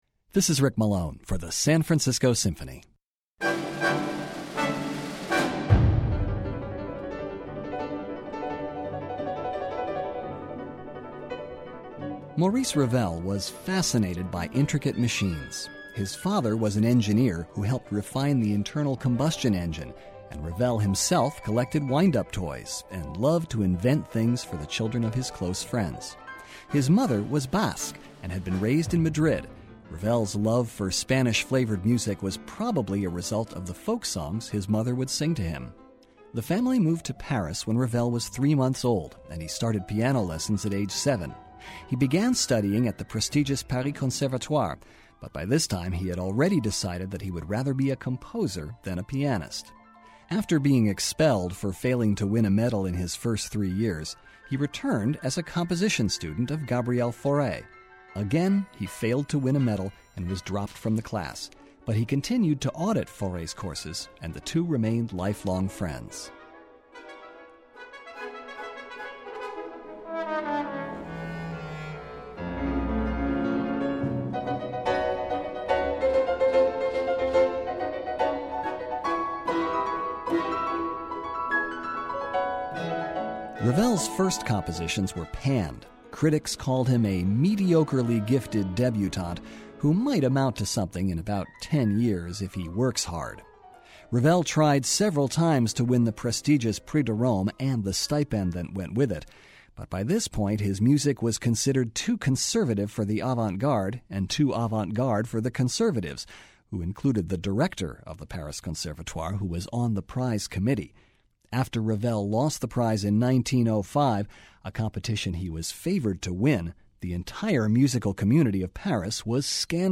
Ravel modeled it after the light, divertimento-like concertos of Mozart and Saint‑Saëns. The Spanish-tinged jazz riffs of the first movement are followed by a gentle and delicate Adagio, and the concerto closes with a bang in its irresistible finale.